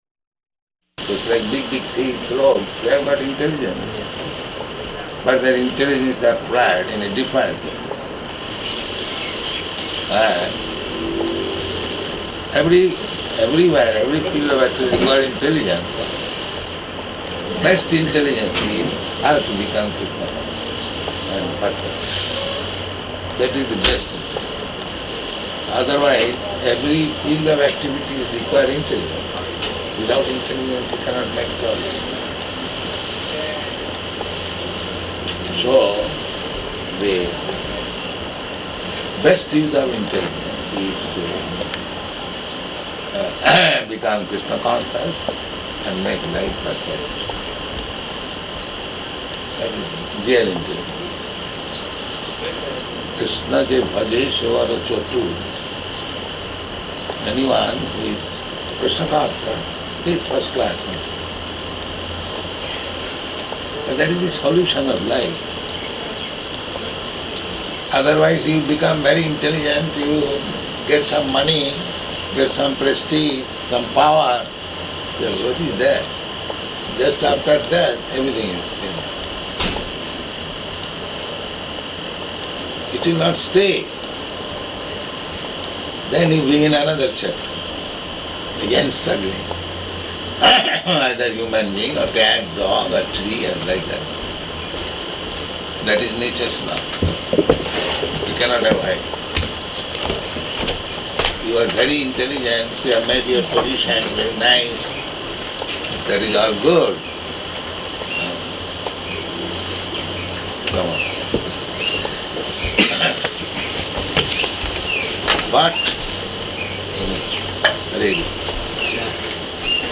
Room Conversation
Room Conversation --:-- --:-- Type: Conversation Dated: June 18th 1975 Location: Honolulu Audio file: 750618R1.HON.mp3 Prabhupāda: ...just like big, big tree grows.